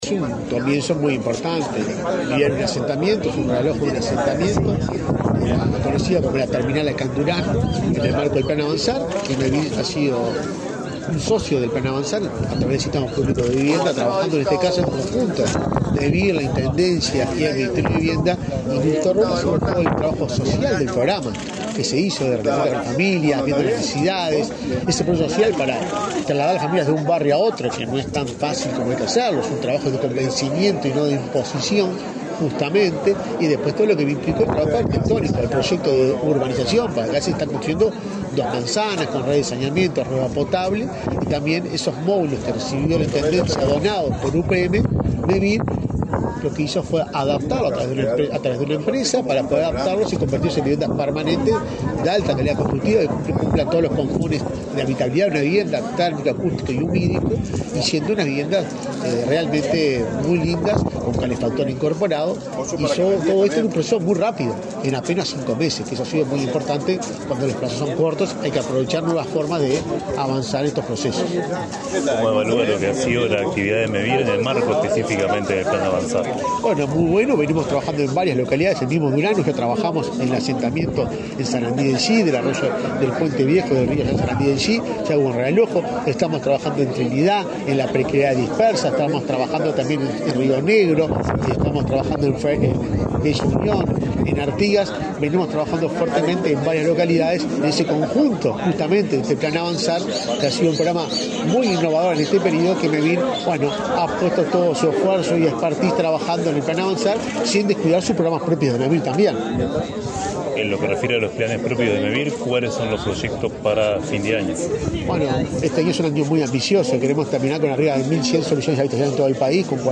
Declaraciones del presidente de Mevir, Juan Pablo Delgado
El presidente de Mevir, Juan Pablo Delgado, dialogó con la prensa en Durazno, antes de participar en el acto de entrega de 17 viviendas del plan